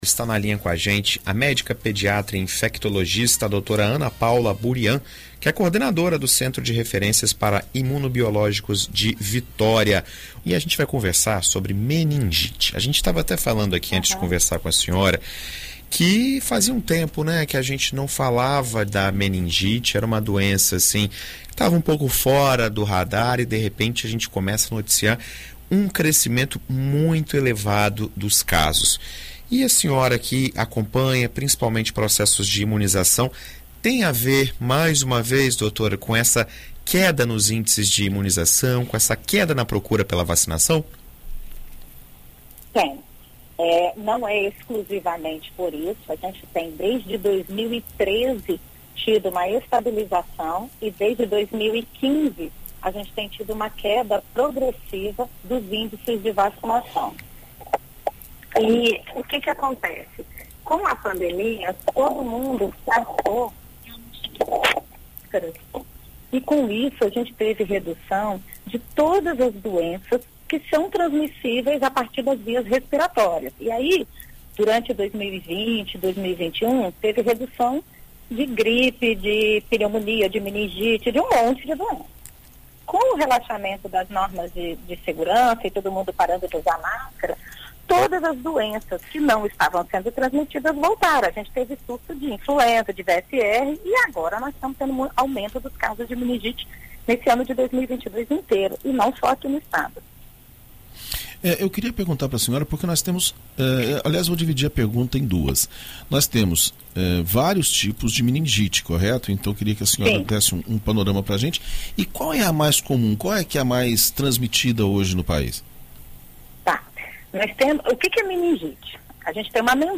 Médica infectologista orienta sobre vacina contra a meningite